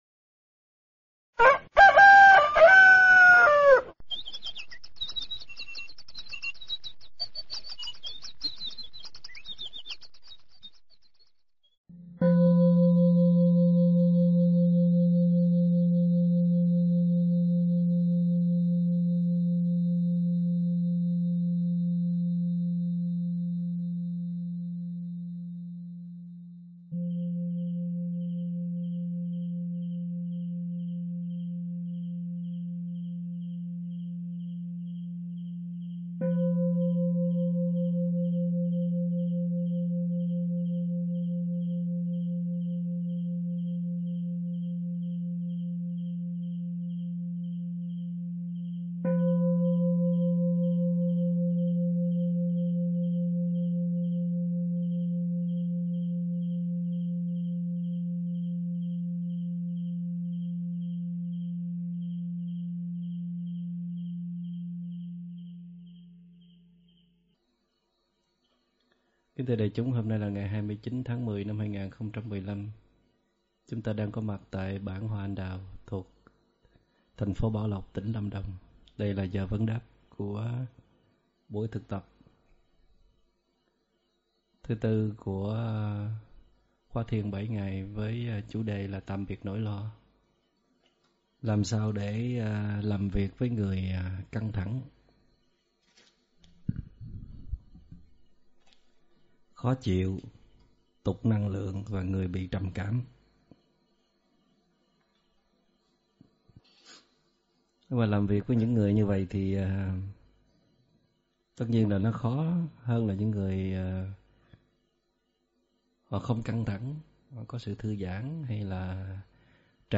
Vấn đáp: Khóa Thiền Tạm Biệt Nỗi Lo
Thuyết pháp